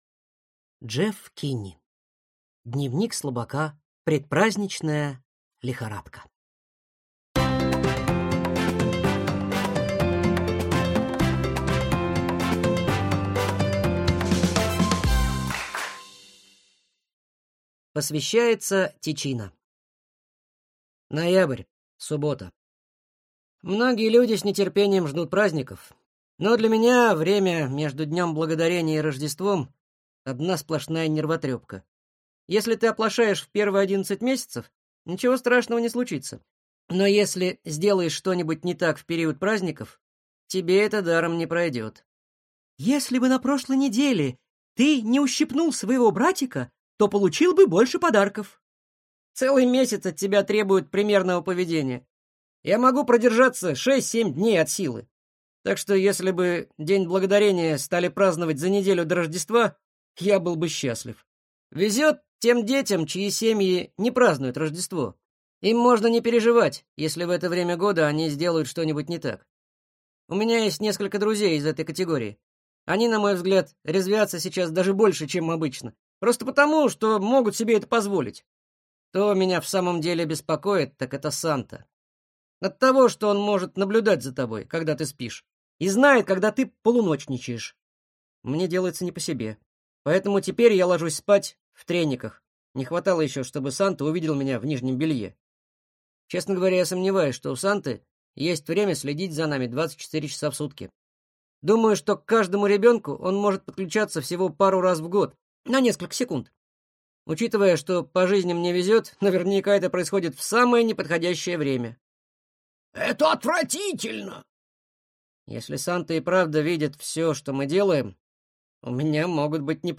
Аудиокнига Дневник слабака. Предпраздничная лихорадка | Библиотека аудиокниг